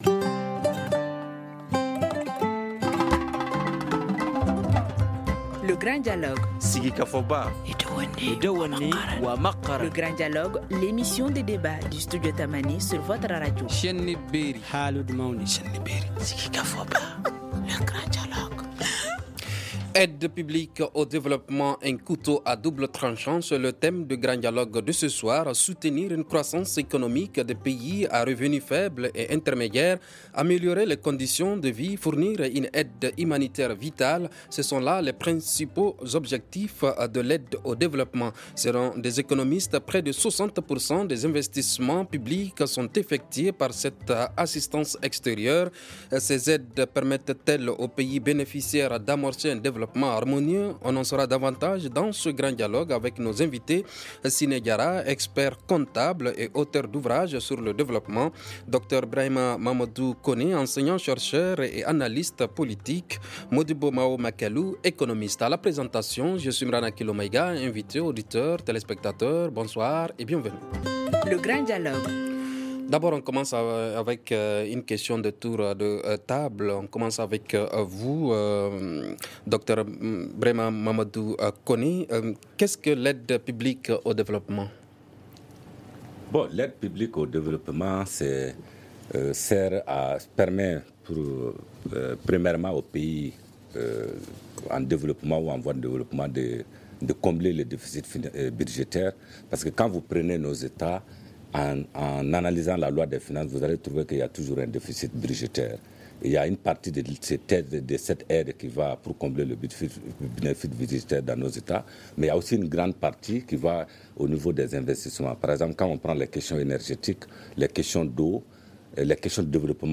C’est la grande question de l’émission de débat le Grand Dialogue.